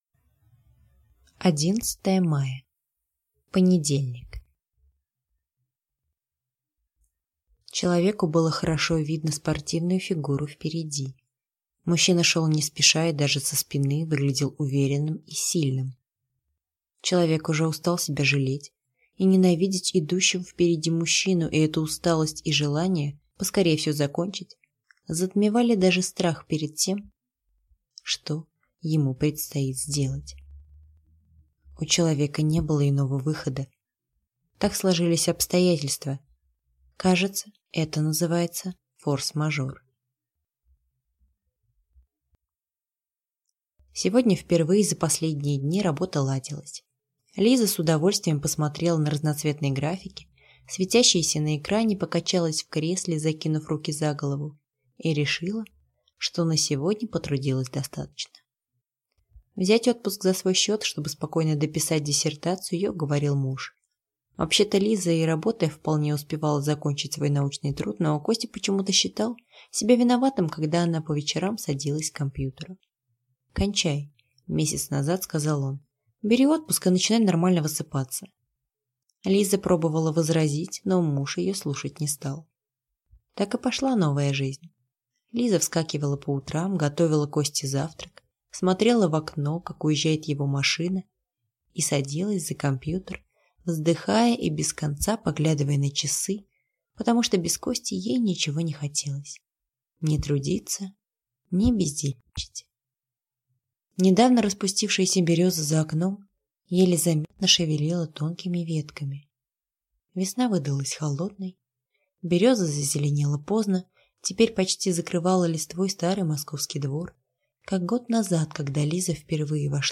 Аудиокнига Сильнее неземной любви | Библиотека аудиокниг